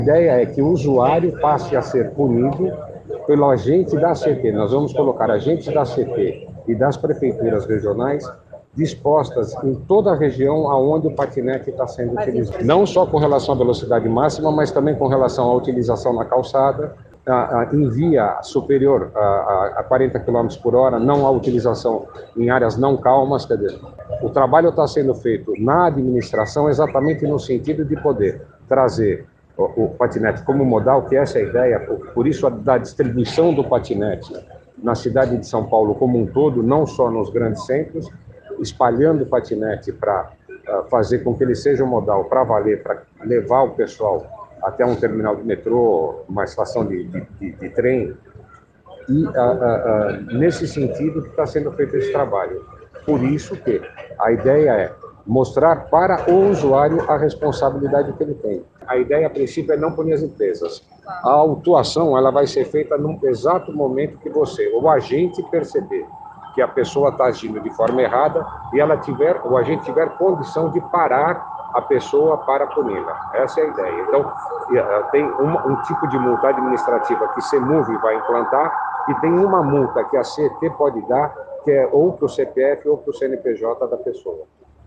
Em entrevista coletiva na manhã deste sábado, o secretário de mobilidade e transportes Edsom Caram, disse que os usuários serão multados em caso de não obediência das regras publicadas neste sábado pelos agentes da CET- Companhia de Engenharia de Tráfego.